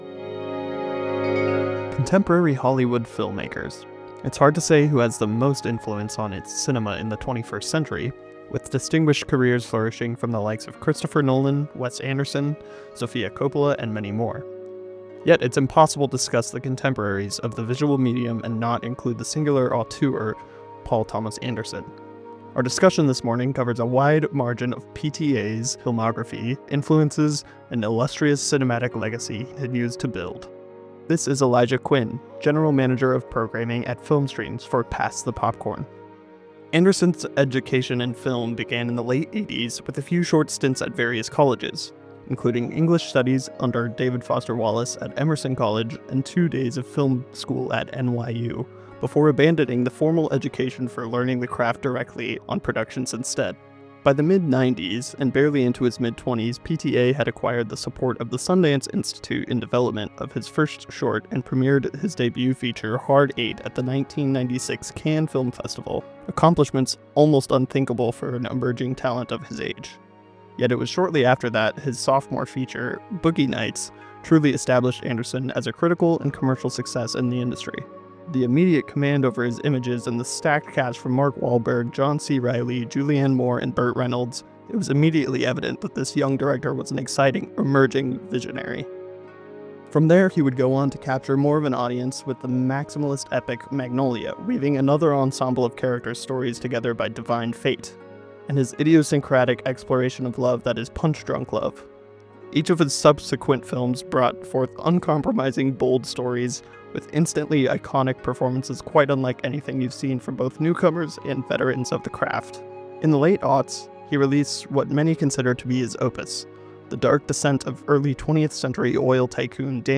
In this week’s Arts Today “Pass the Popcorn” segment, Film Streams highlights the work of contemporary filmmakers and the movies shaping today’s cinema landscape. The conversation explores directors who are pushing boundaries with style, storytelling, and themes that resonate with modern audiences.